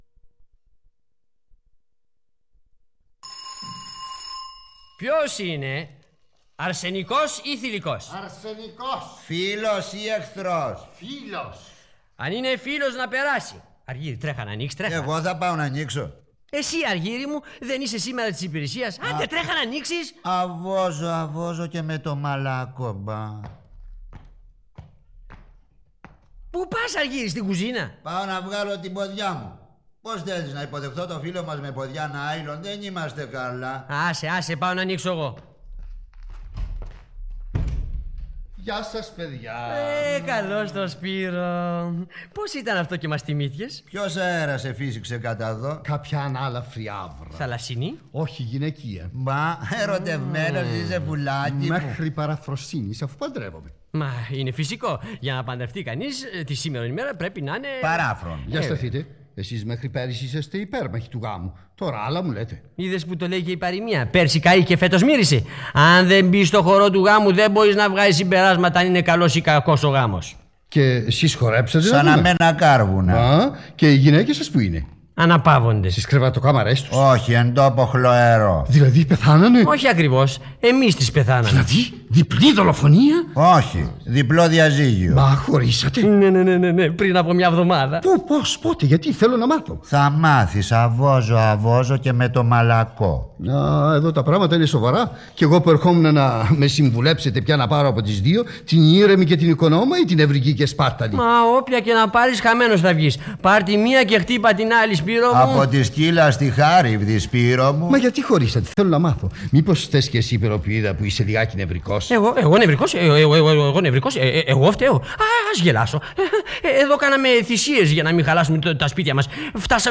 Πηγή: ΕΡΤ «Αρχείο ραδιοφώνου» Ραδιοπρόγραμμα 1954